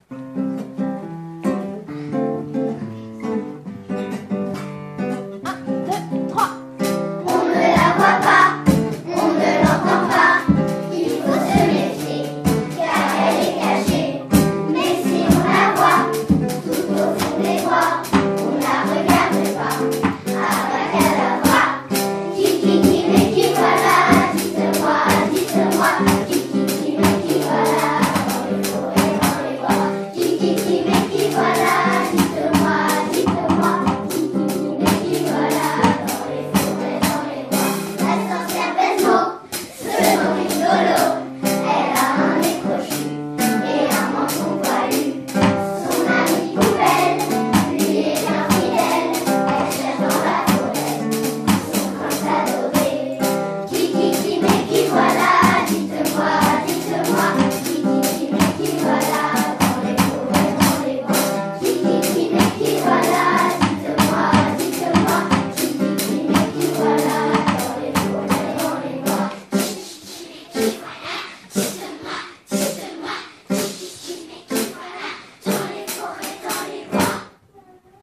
Le conte leur a plu, alors les élèves ont eu envie de s’investir dans sa mise en chants et en musique.
Les représentations, devant les élèves à l’école puis devant les parents à l’auditorium, ont été un réel plaisir et un grand succès.